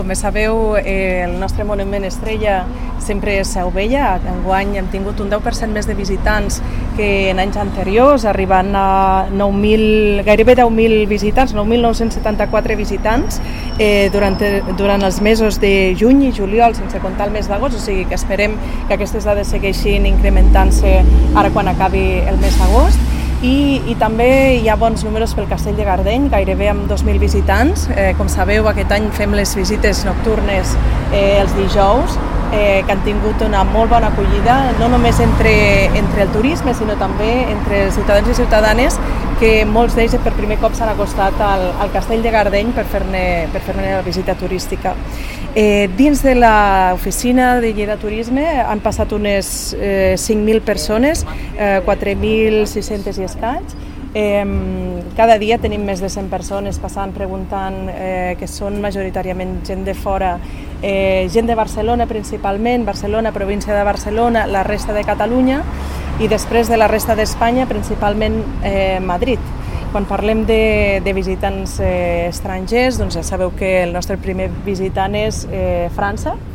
Tall de veu de l'alcaldessa accidental, Cristina Morón, sobre la campanya turística d’estiu i del nou bus turístic.